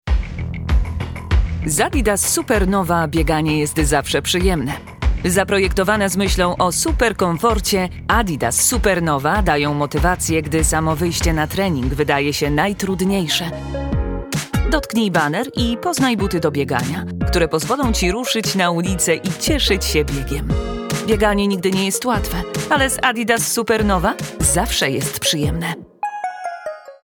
Brazilian Commercial Voice Talent for IQ Option Tutorials
Confident, easy-to-follow voiceovers tailored for IQ Option instructional videos. Hear dynamic narration that guides users smoothly - sample the talent now.